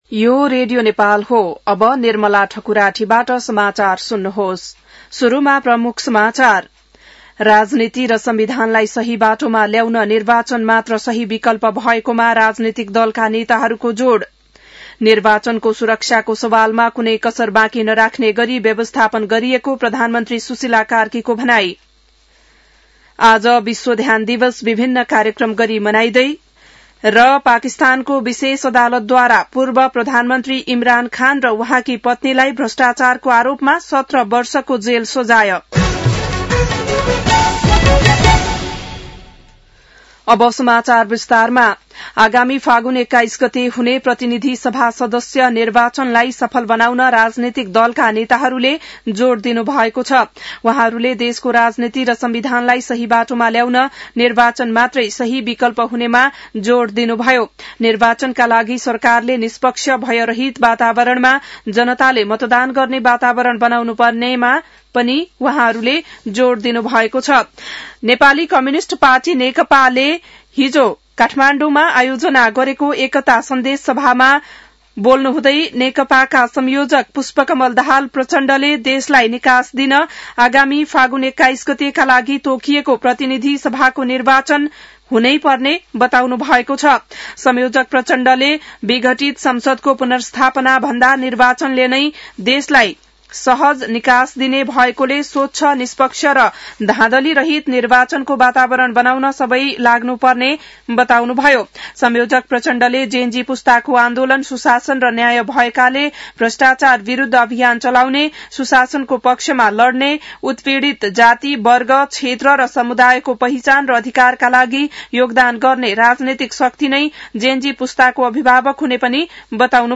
बिहान ९ बजेको नेपाली समाचार : ६ पुष , २०८२